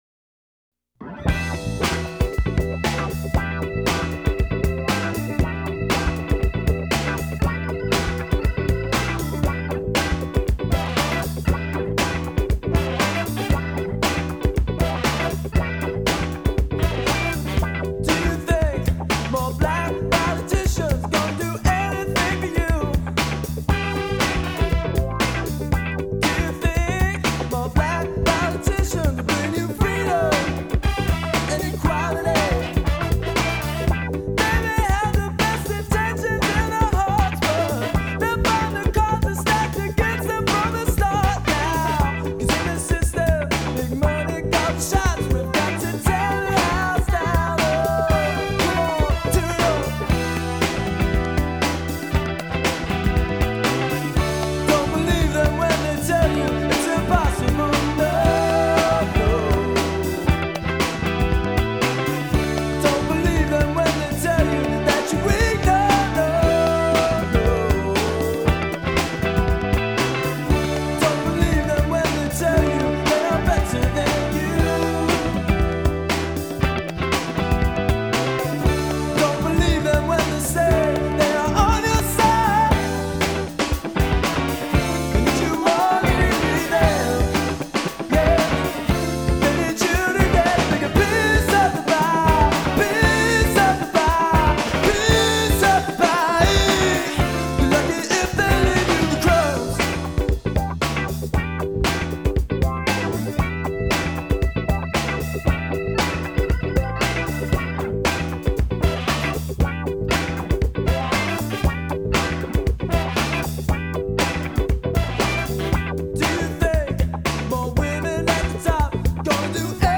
horns